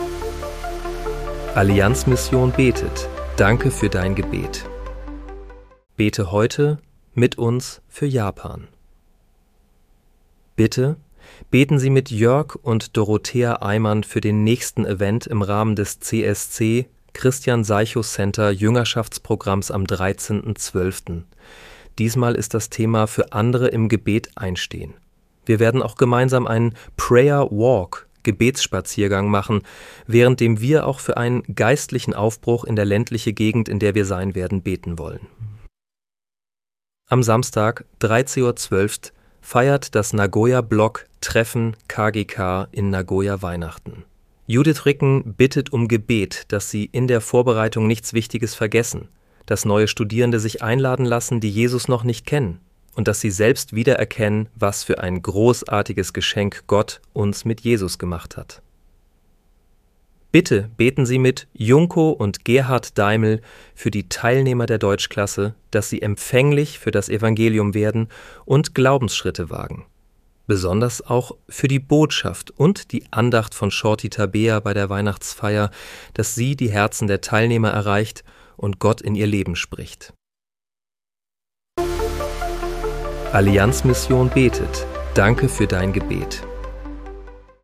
Bete am 13. Dezember 2025 mit uns für Japan. (KI-generiert mit der